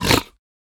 sounds / mob / piglin / hurt2.ogg
hurt2.ogg